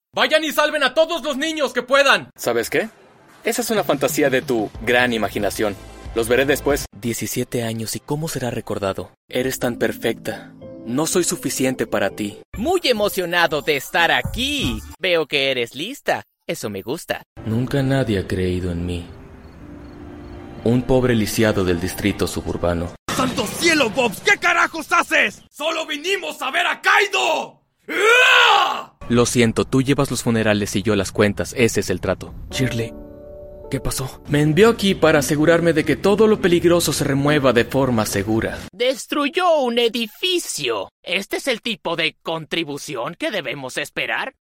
una voz versátil y barítono en español latino
Programas de TV
Mic: AT-2035